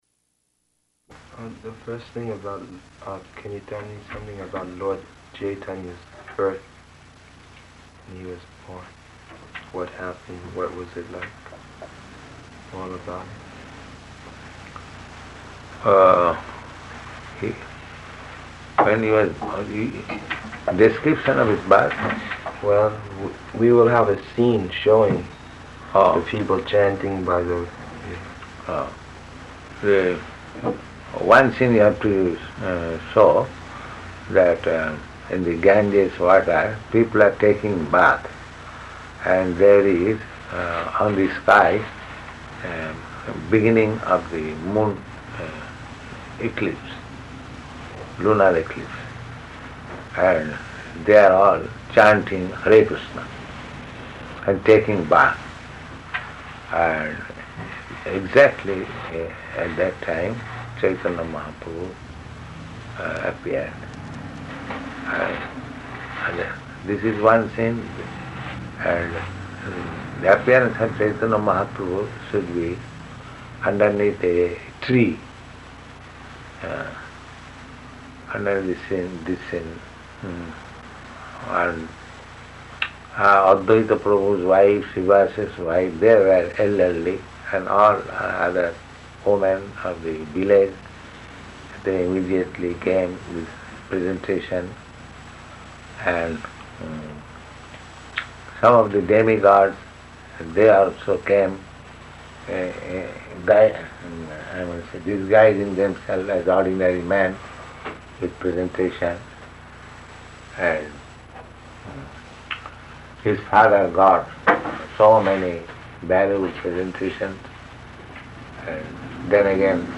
-- Type: Purport Dated: August 4th 1969 Location: Los Angeles Audio file